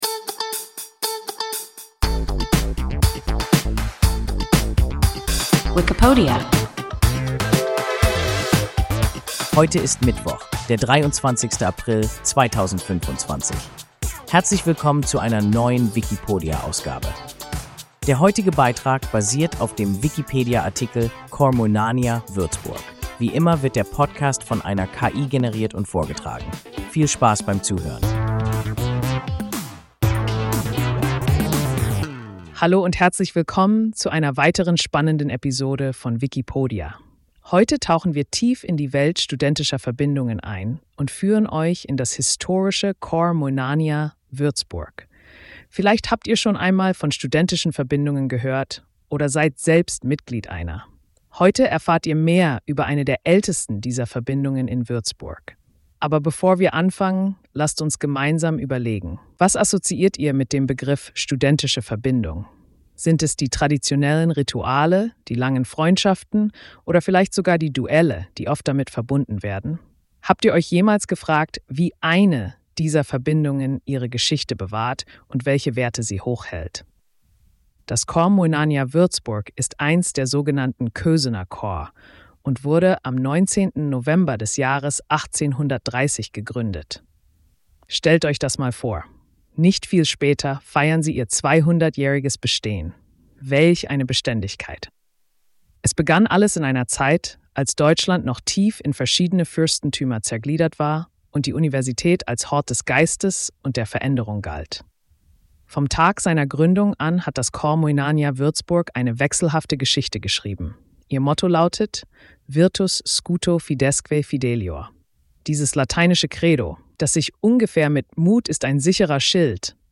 Corps Moenania Würzburg – WIKIPODIA – ein KI Podcast